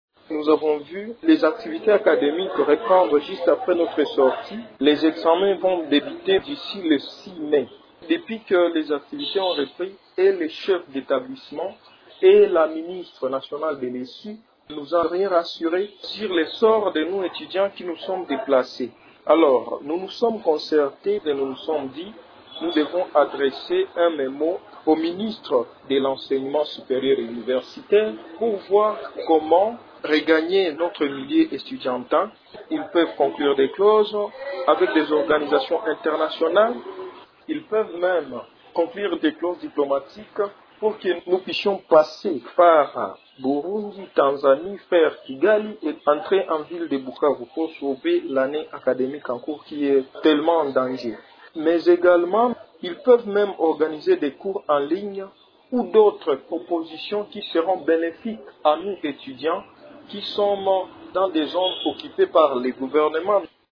Intervenant sur Radio Okapi dimanche 20 avril, l'un d'eux a appelé l'intervention des autorités congolaises.